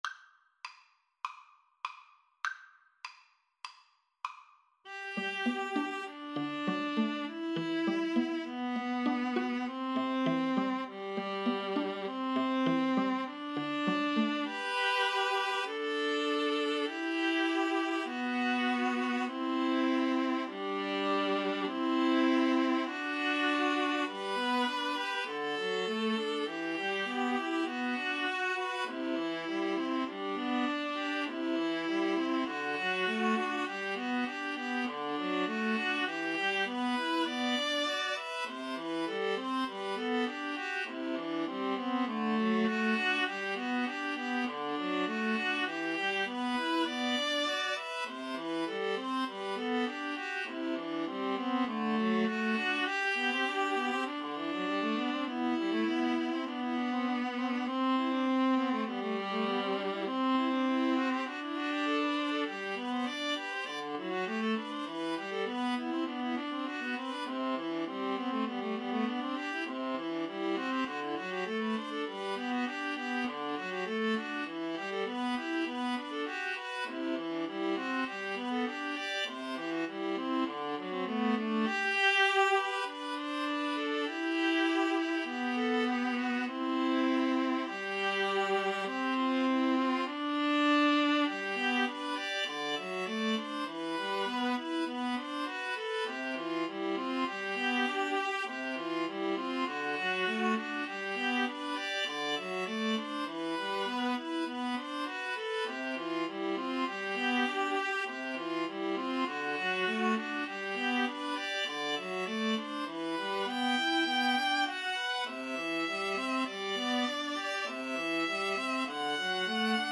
Classical